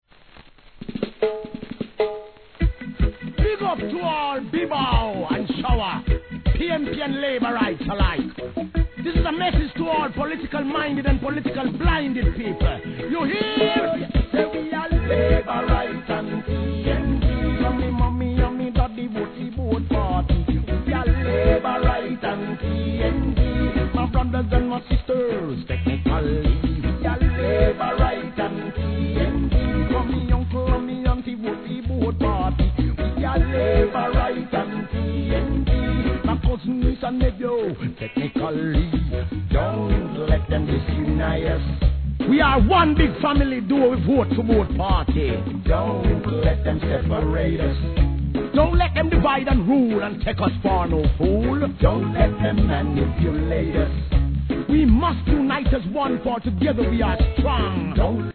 REGGAE
随所に演説を挿入したユニークな形でのメッセージ!